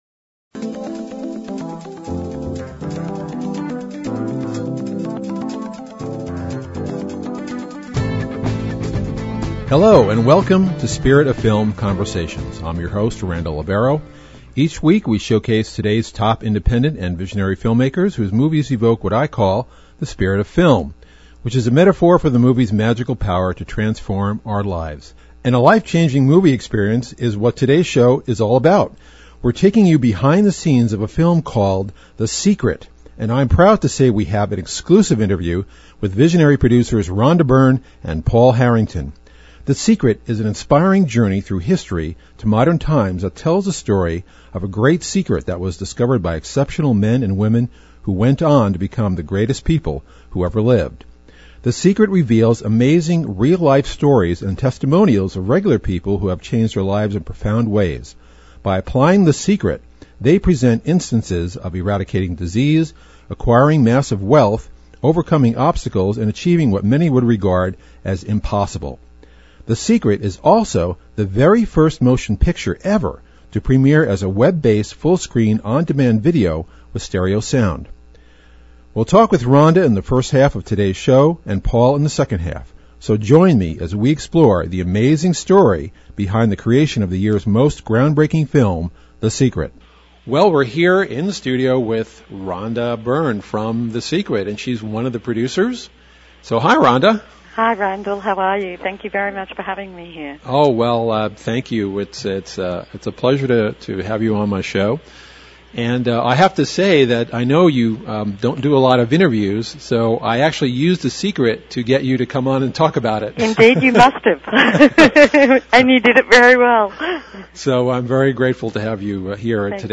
Interview with the creators of The Secret